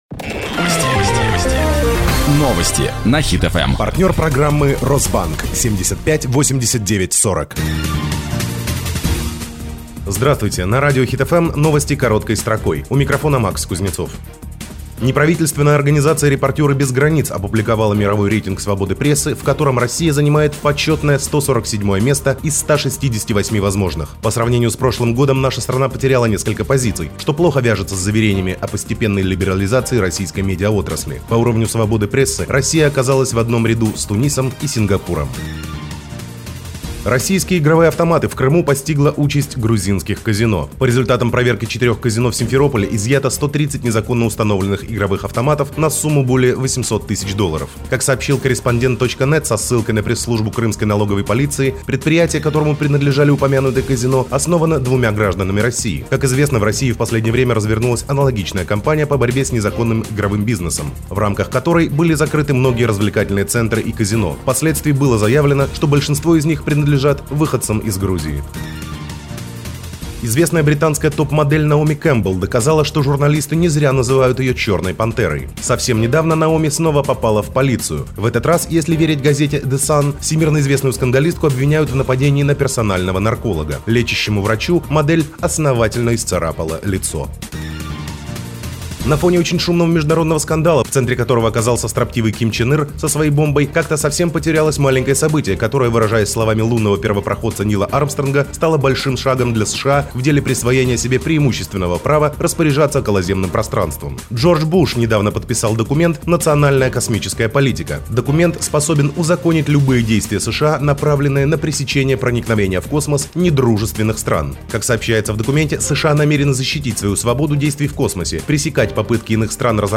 Ведение программы: Программа ведется в деловом строгом стиле.
В начале и в конце программы звучит рекламный ролик спонсора.